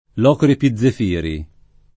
l0kri epizzef&ri] (alla lat. Locri Epizefirii [l0kri epizzef&ri-i]) top. stor. (Cal.) — città della Magna Grecia, fondata dai locresi di Grecia (sec. VII a. C.), distrutta dai saraceni (sec. VII d. C.) — Locri nome dato dal 1934 a Gerace Marina, sorta presso le rovine dell’antica città